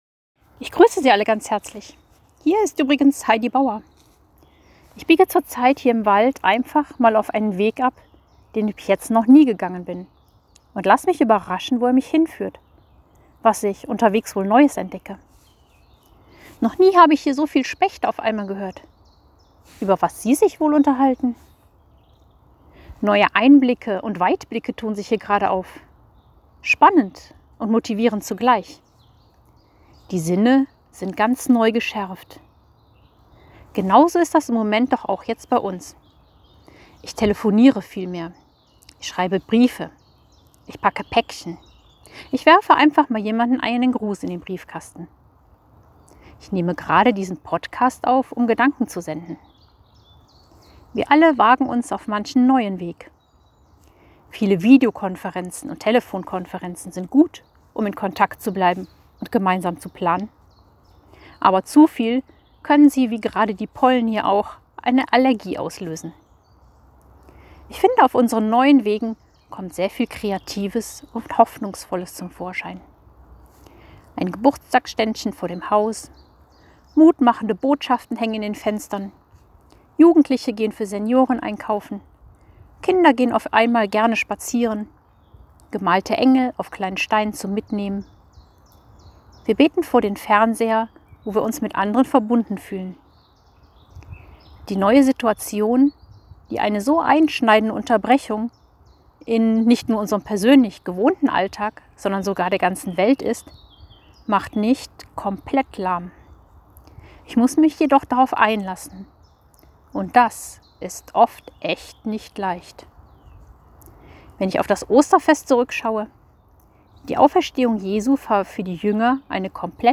Ansprache